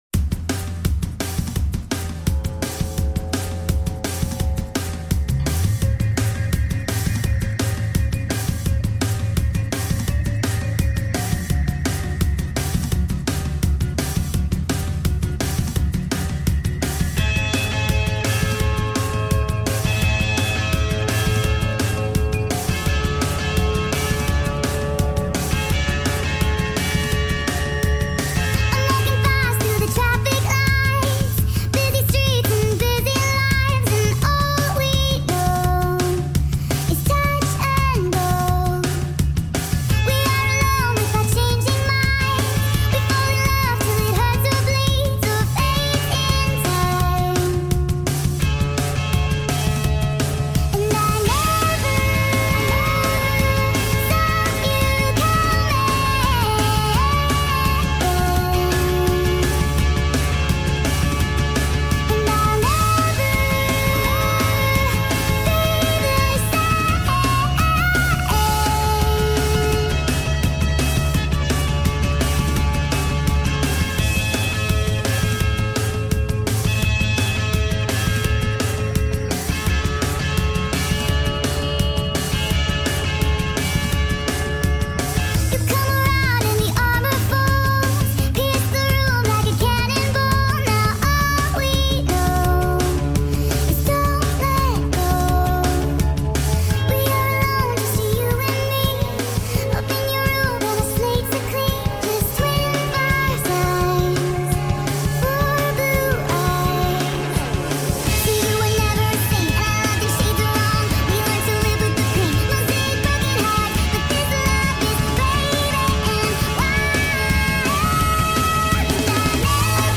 up full song